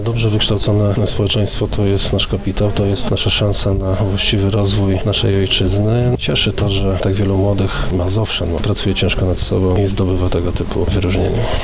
Dlaczego ważne jest aby młodzież zdobywała wiedzę mówi Wicewojewoda Mazowiecki Artur Standowicz: